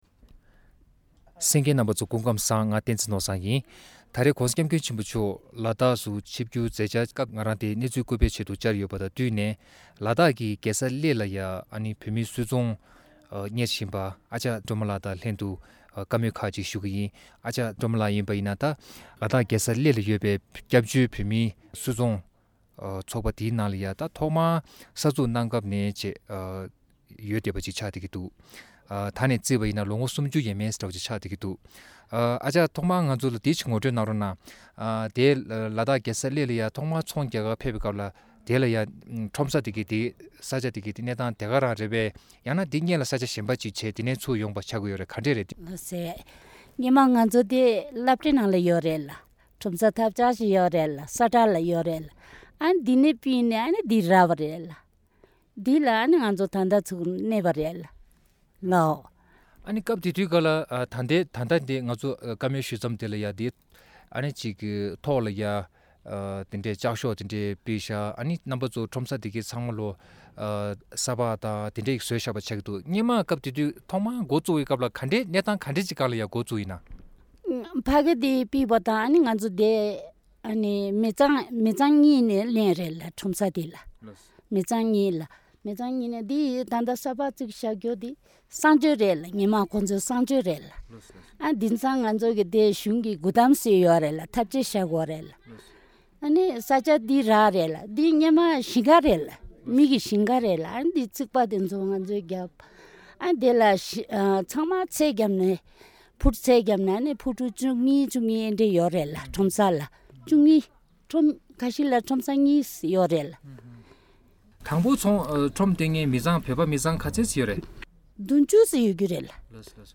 Tibetan Business Face New Challenges: Special Report